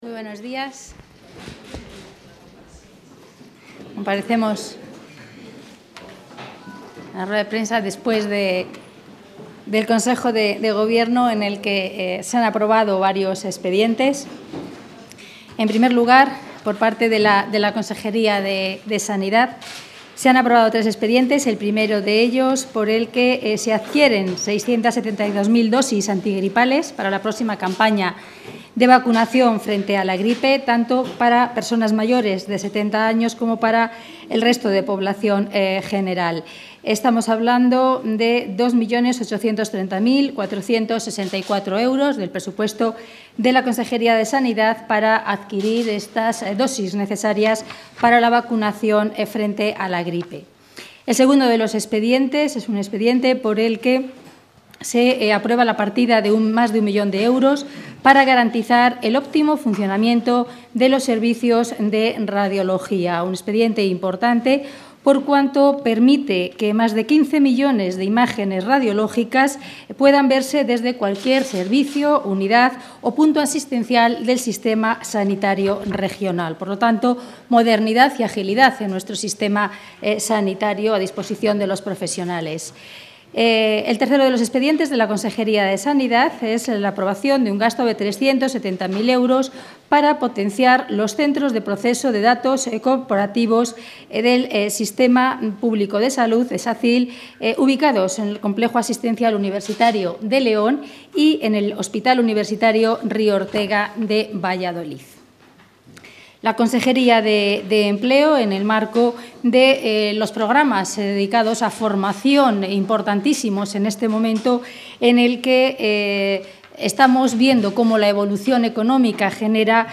Audio rueda de prensa posterior al Consejo de Gobierno.